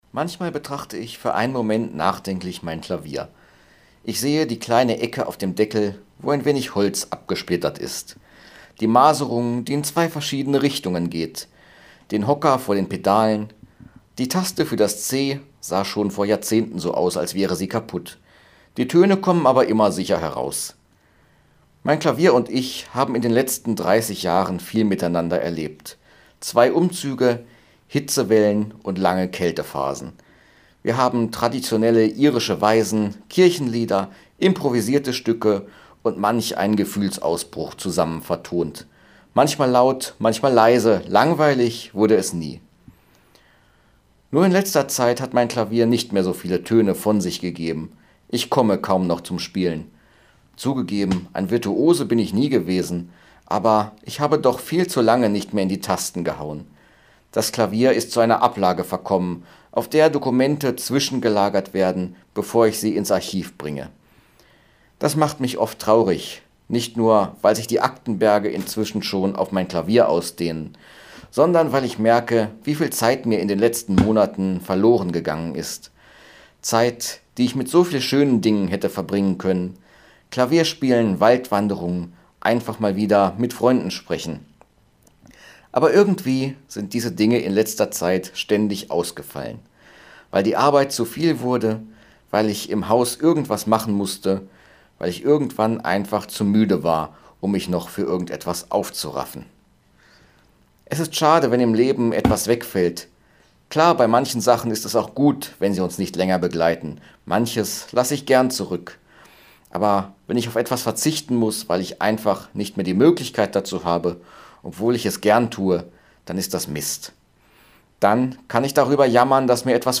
Radioandacht vom 2. August